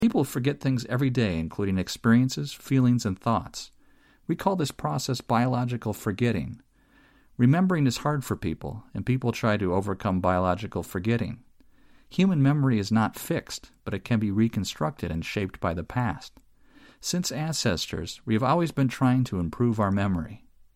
You will hear an Interview/Lecture.